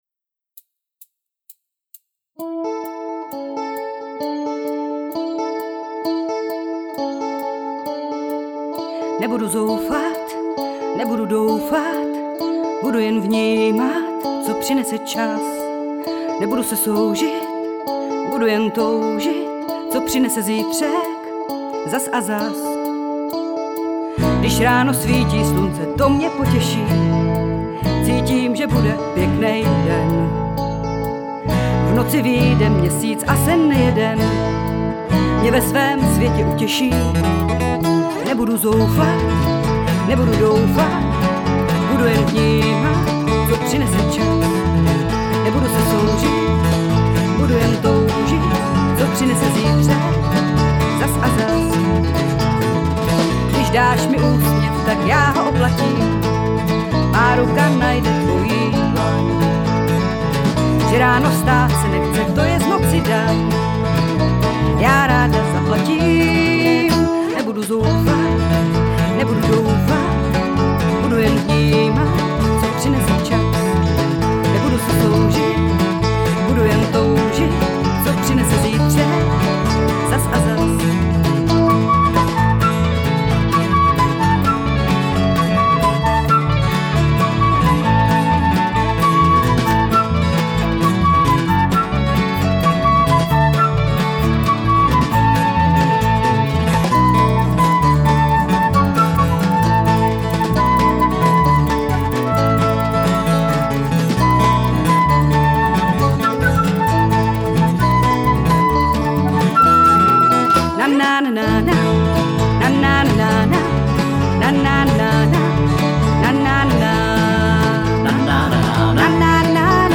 Festival trampských písní